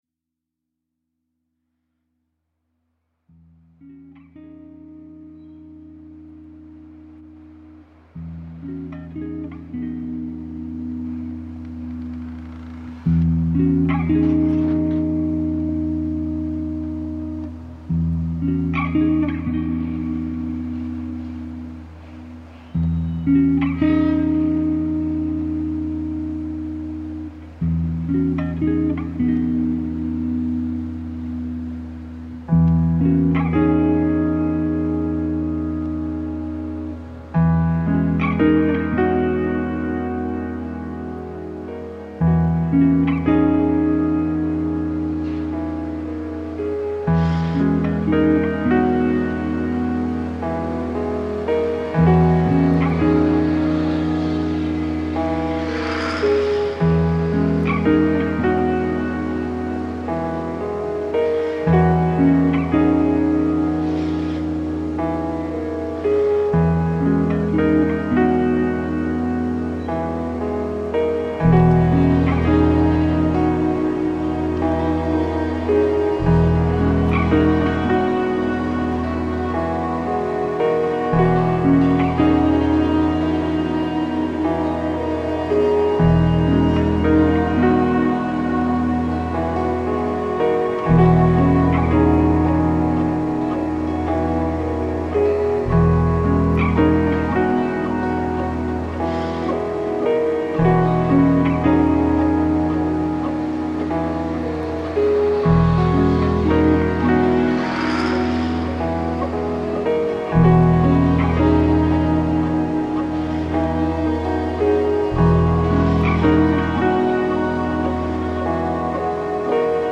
electronic and ambient music project